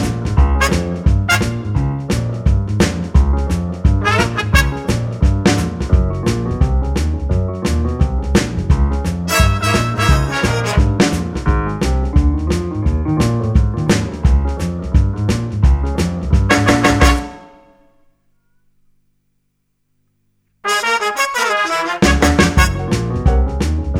no Backing Vocals Oldies (Female) 2:35 Buy £1.50